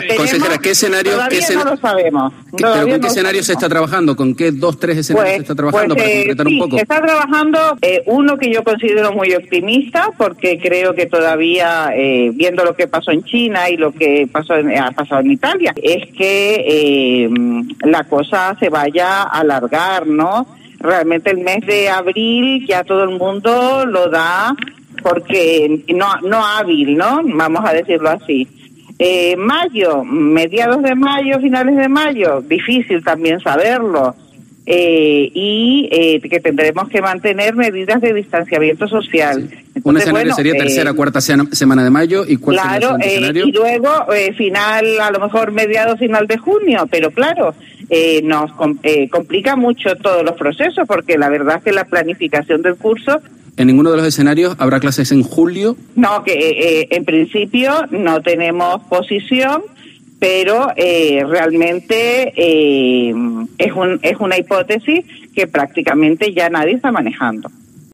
La consejera de Educación del Gobierno de Canarias, María José Guerra, asegura en una entrevista en COPE Canarias que “dadas las circunstancias y teniendo en cuenta las condiciones climáticas, y lo complicado que es la reprogramación del curso, el alargar hasta julio queda prácticamente descartado”